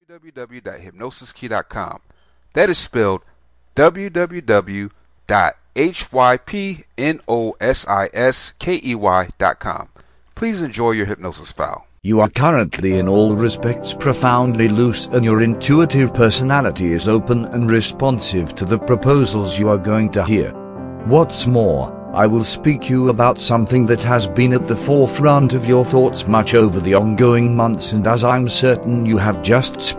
Newly Single Self Hypnosis MP3